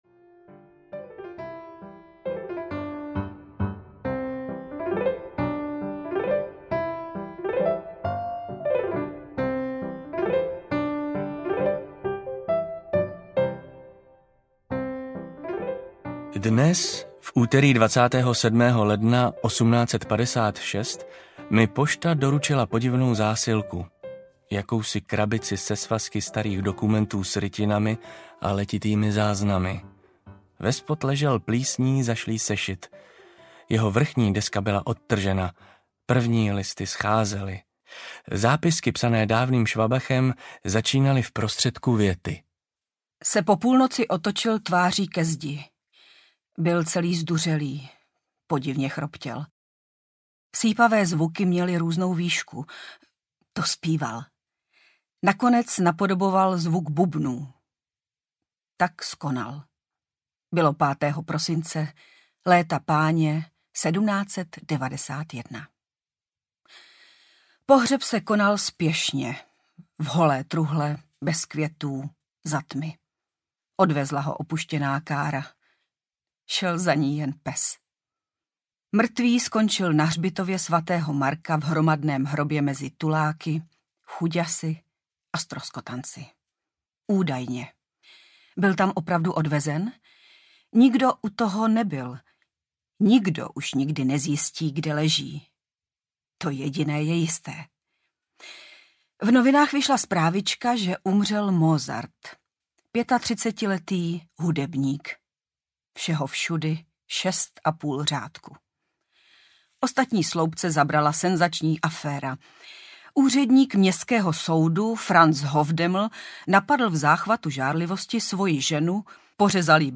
Ukázka z knihy
Audioknihu doprovází Mozartova hudba z archivu Supraphonu.